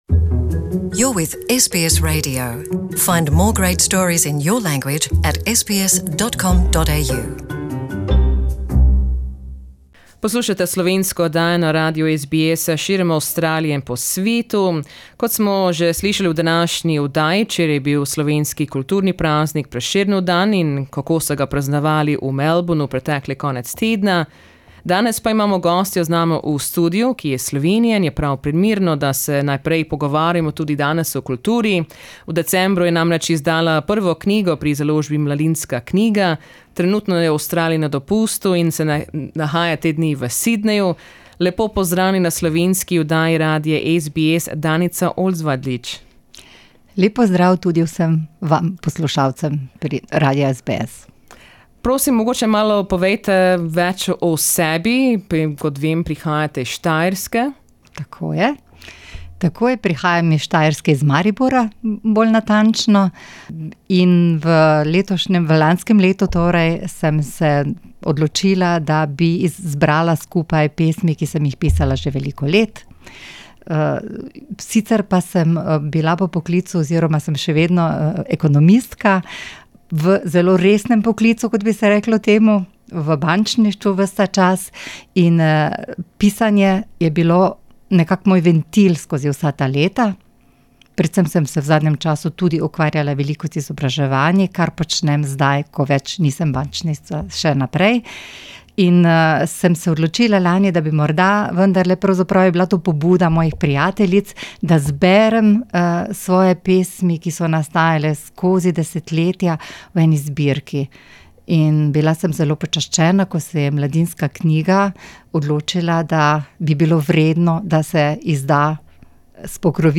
in our SBS Radio studios in Sydney